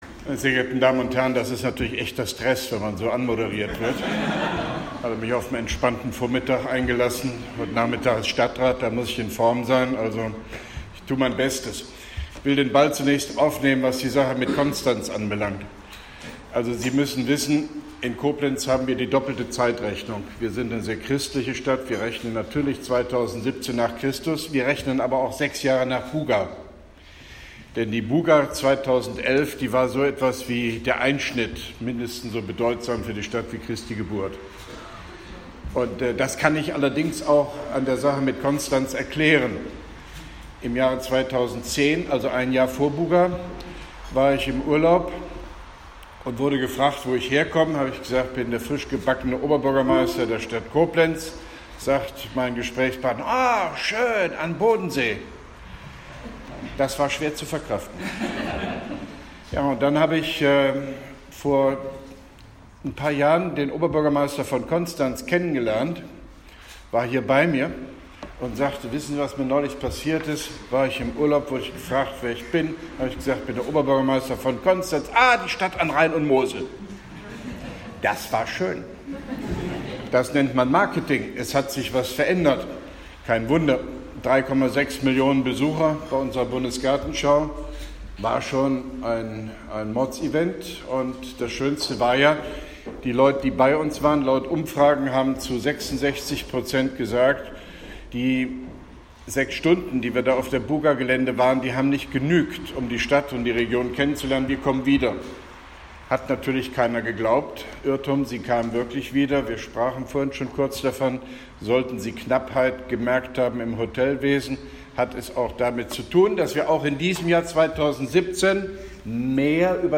OB Hofmann-Göttig begrüßt die Teilnehmen-den einer Informatik Fachveranstaltung an der Uni Koblenz
Grußwort von OB Hofmann-Göttig bei der 7. Excellence-in-Service-Konferenz EXIS 2017, Koblenz 28.09.2017